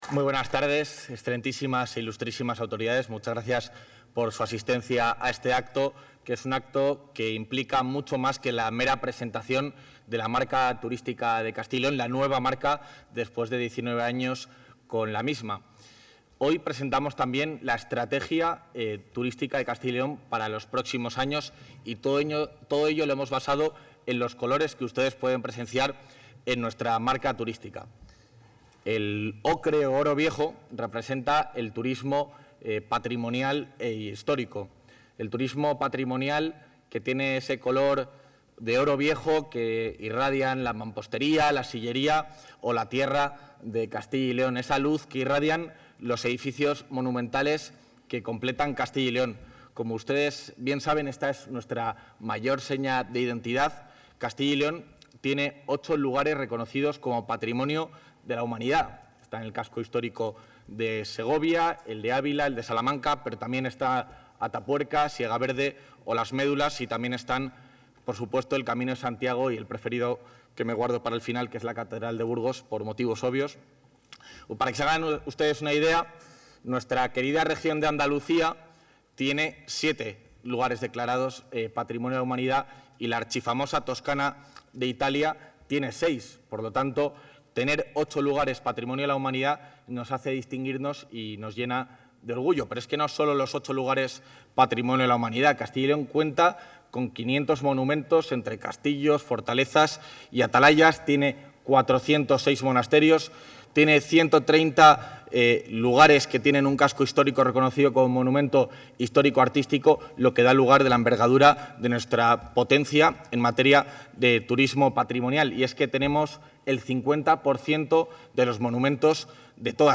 El vicepresidente de la Junta de Castilla y León, Juan García-Gallardo, ha presentado hoy en el Centro Cultural Miguel Delibes de...
Intervención del vicepresidente de la Junta.